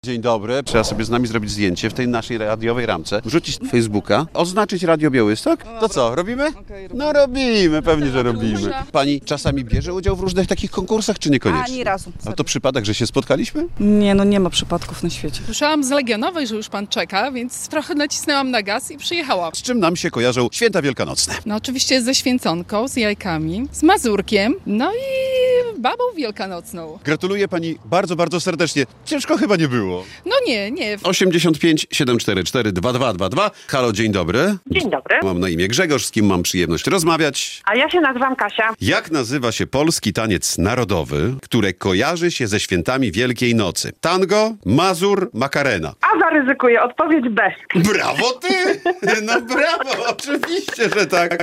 Wielkanocne konkursy Polskiego Radia Białystok - relacja